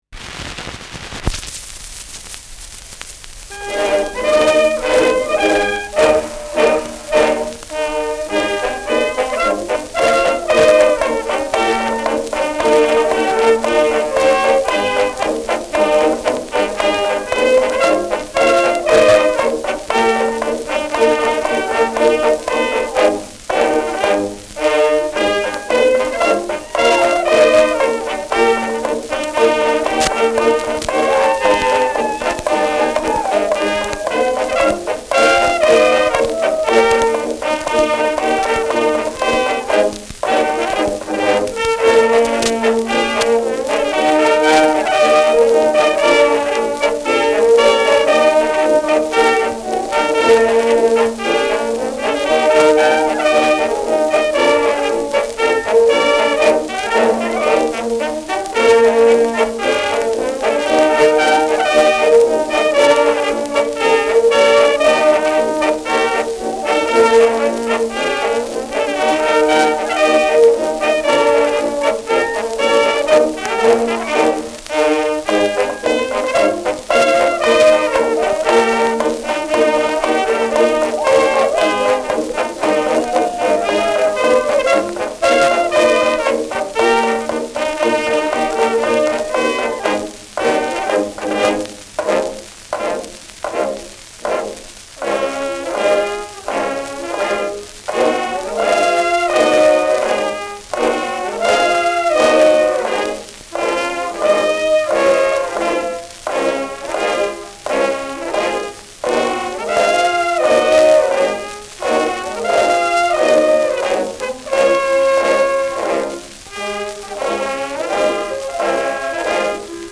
Schellackplatte
Vereinzelt leicht kratzendes Störgeräusch
Das zwischenzeitlich zu hörende rhythmische Klopfen soll wohl Holzhacken (gemäß Titel) imitieren.